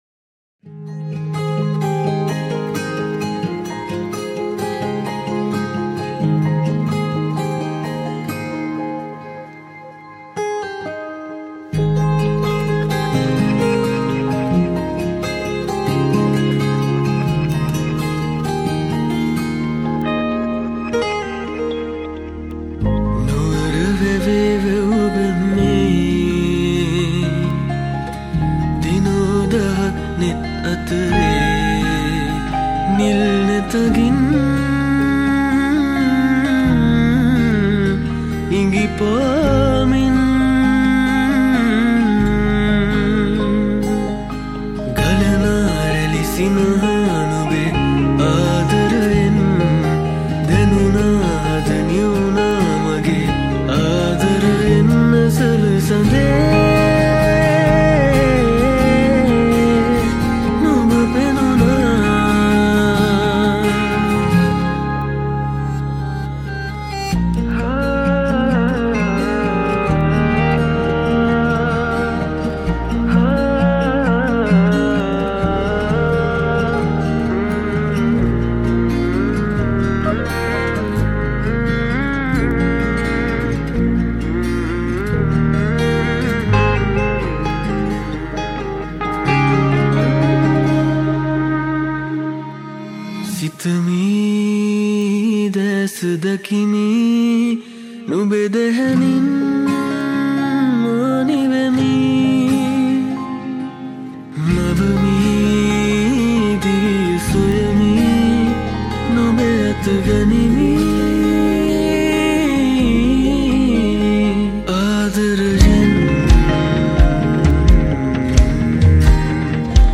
Acoustic and Electric Guitar
Piano and Drums